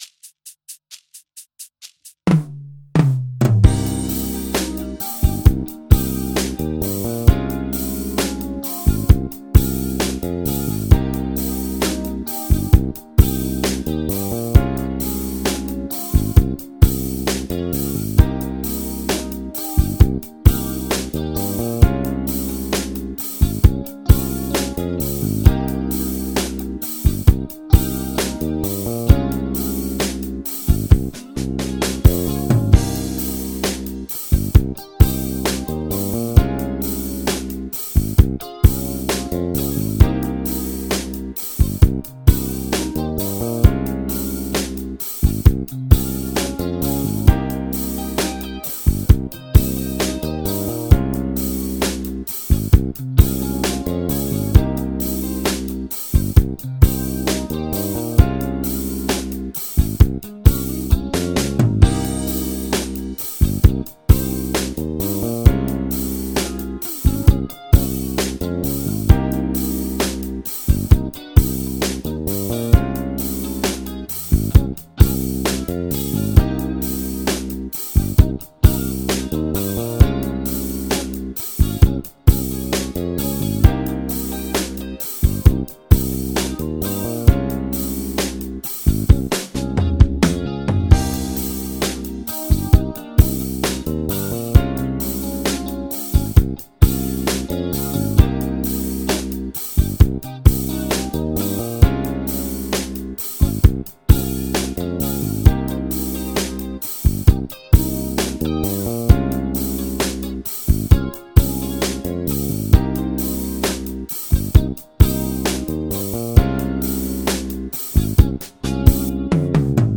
フリジアン・スケール ギタースケールハンドブック -島村楽器